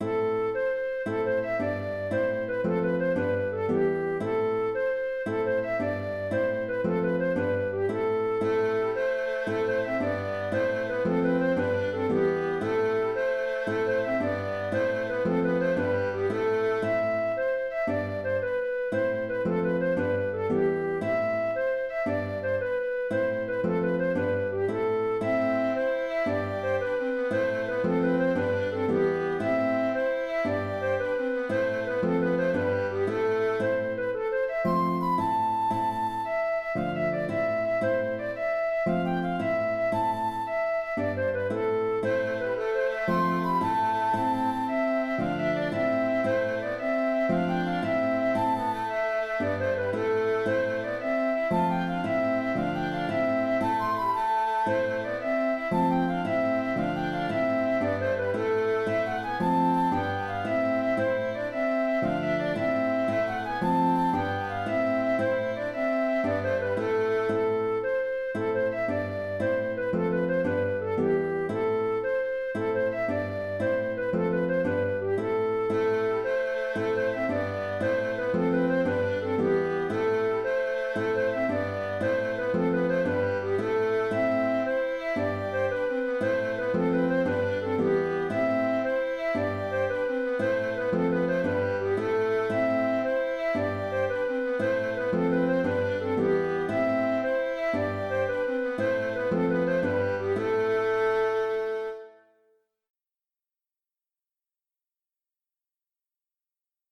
Ce rondeau est composé de deux parties.
La dernière phrase commence par une montée en mineur mélodique, avec un sol#, qu’il est plus facile d’effectuer, pour les accordéonistes diato, avec un trois rangs.
La partie traditionnelle du morceau devient comme un refrain.